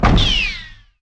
UnusedSM64TrapDoorOpening.ogg.mp3